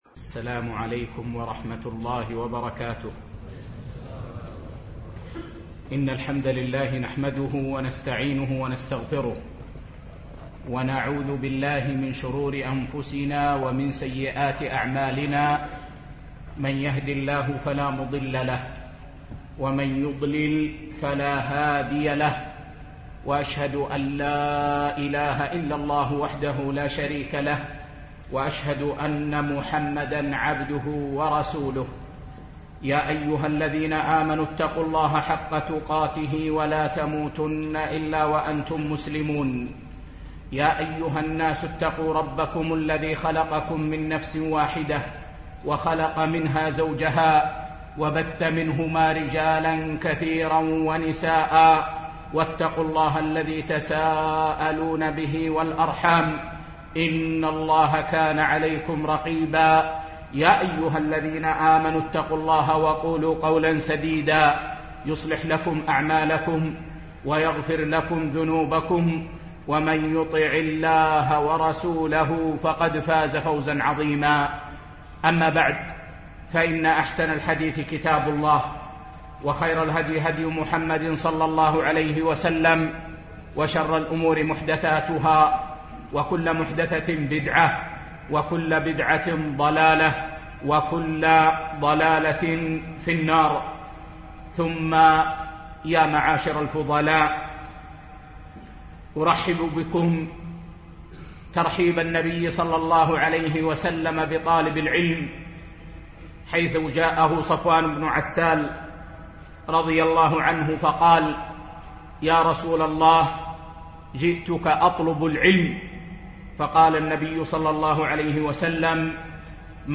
بمسجد محمد سالم بن بخيت، بدبي